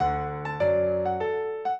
piano
minuet4-10.wav